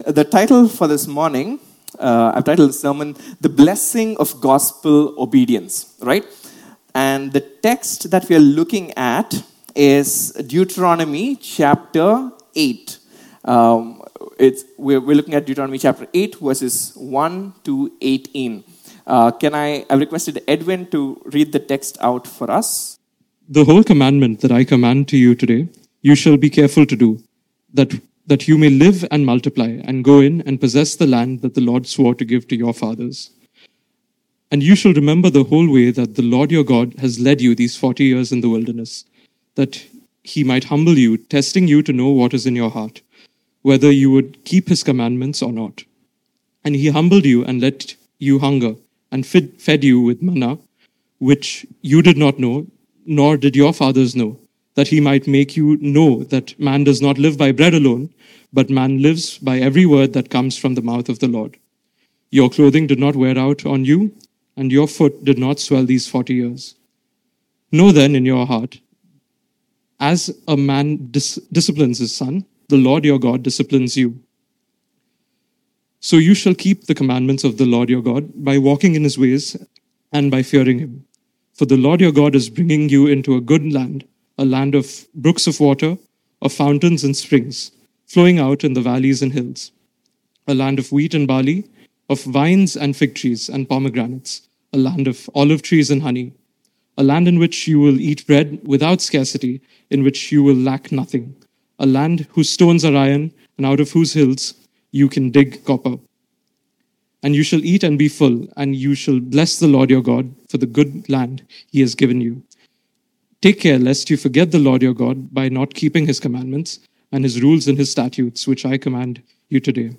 A message from the series "Explore Sermons."